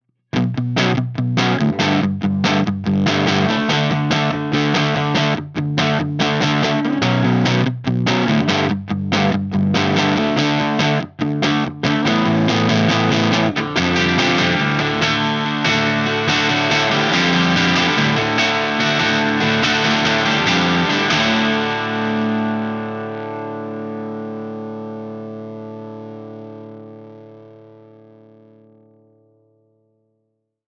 JTM45 Dirty Mesa V30
What makes this clip difficult is some speakers work well with the PM's in the first half while others handle the open chord work much better.
JTM_DIRTY_MesaV30.mp3